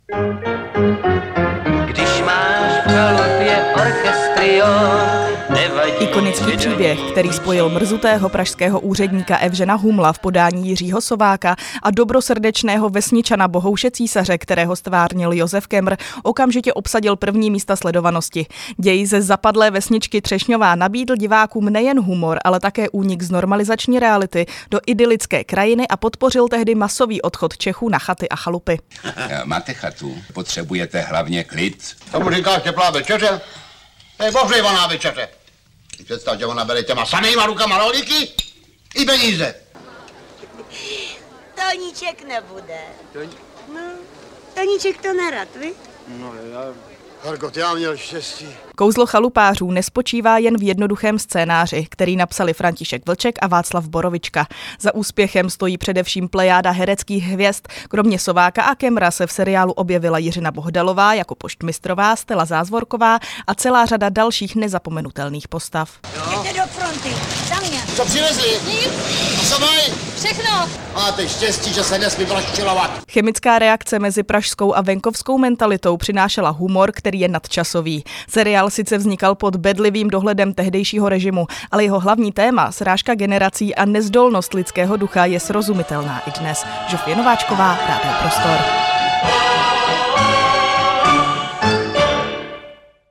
Rozhovor s herečkou Mahulenou Bočanovou